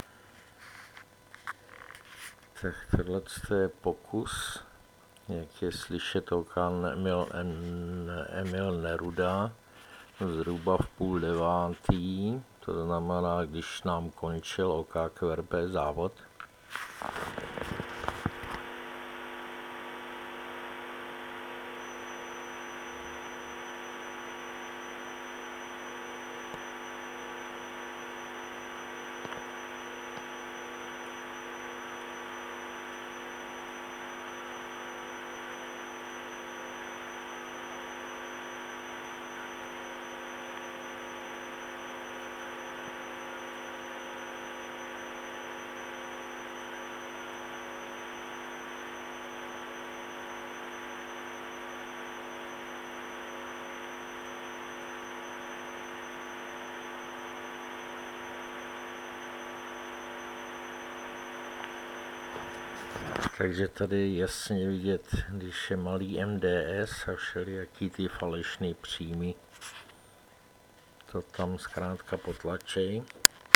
No poslechněte si, jak PIXID 612 slyší v době konce OK QRP závodu maják OK0EN se 150mW.